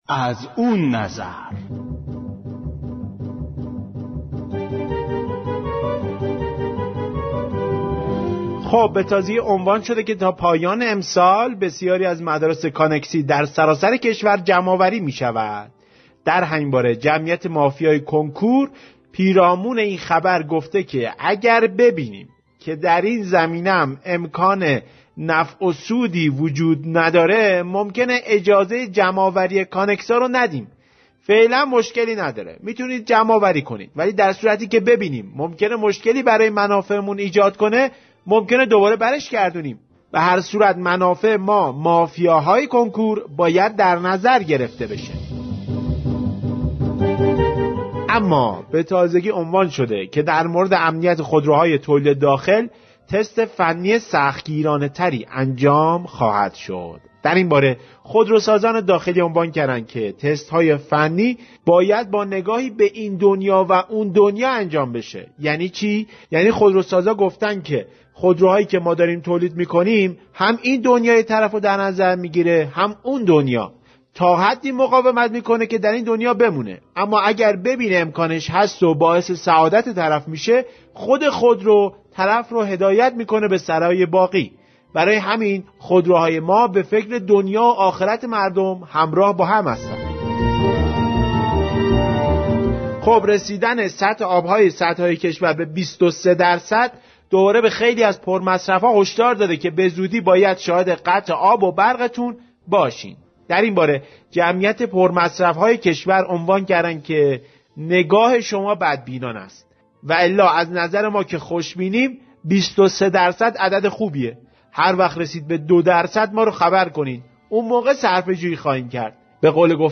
بخش طنز برنامه رویدادهای هفته
طنز